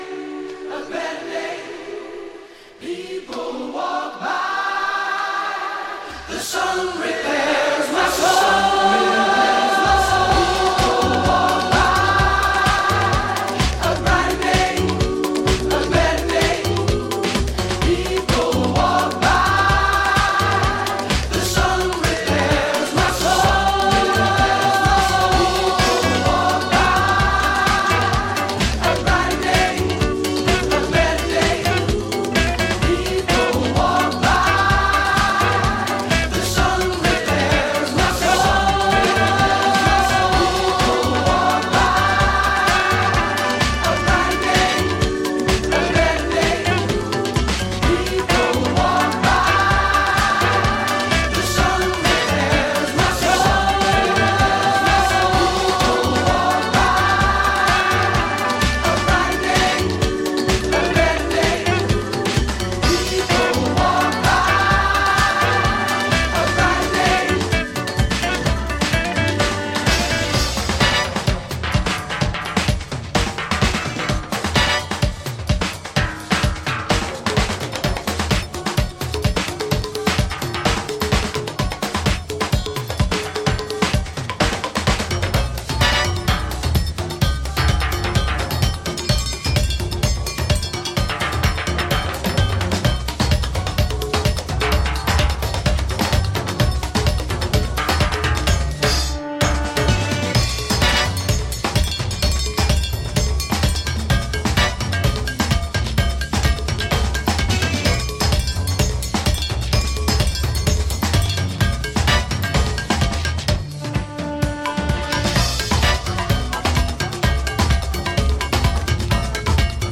2. > HOUSE・TECHNO
ジャンル(スタイル) DISCO / HOUSE